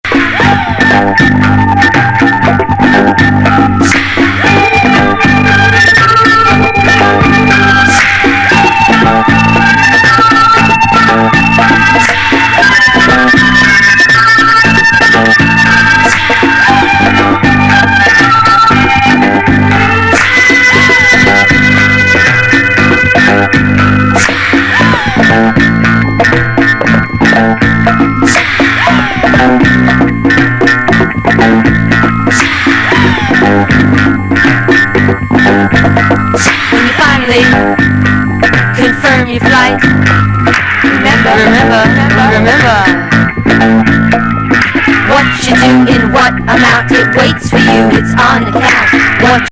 USガールズ・ノーウェイビー・デュオ
危険ディスコ